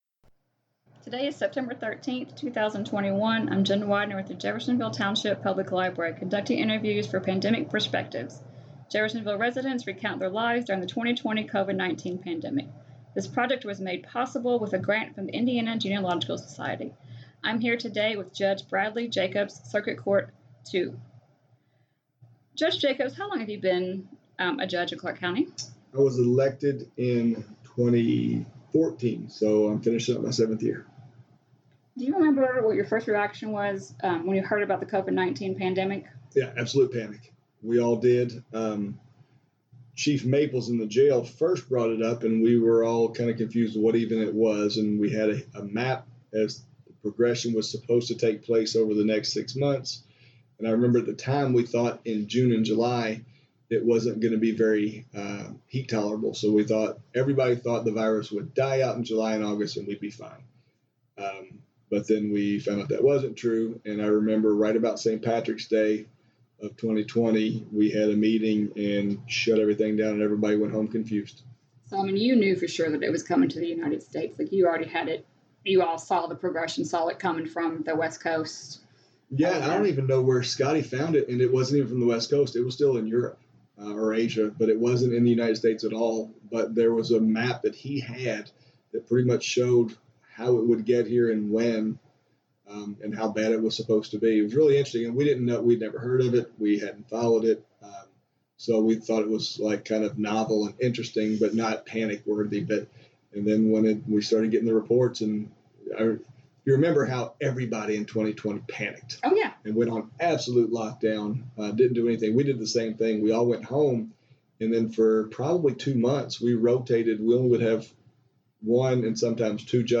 Oral Histories As the Covid-19 pandemic progressed and continued the need to capture people's stories grew.